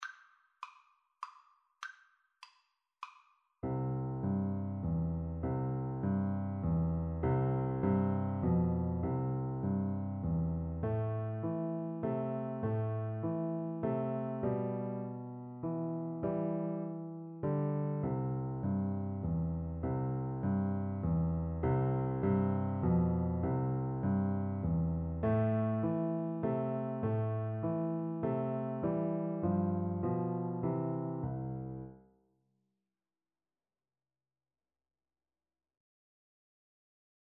3/4 (View more 3/4 Music)
Moderato
Piano Duet  (View more Beginners Piano Duet Music)